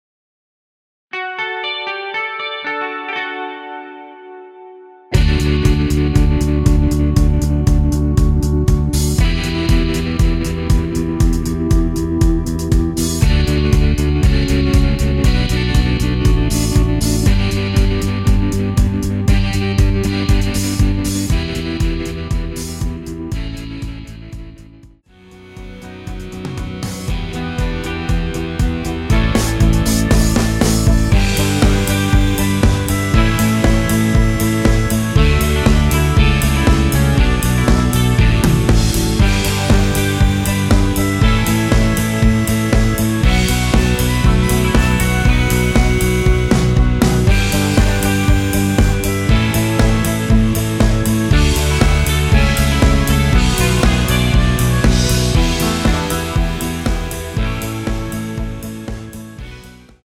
원키에서(+3)올린 MR입니다.
D
앞부분30초, 뒷부분30초씩 편집해서 올려 드리고 있습니다.
중간에 음이 끈어지고 다시 나오는 이유는